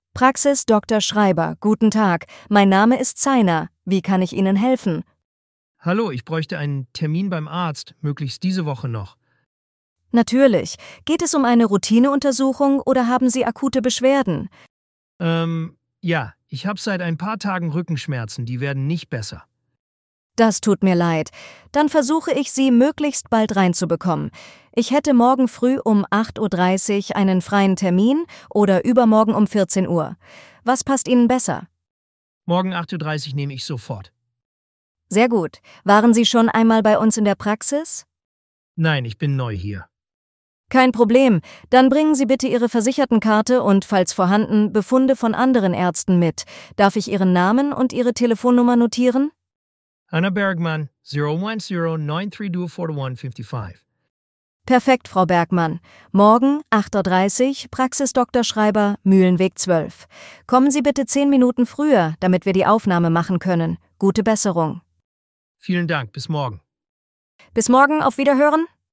Wählen Sie Ihre Sprache und hören Sie echte KI-Gespräche.
voice-demo-healthcare.mp3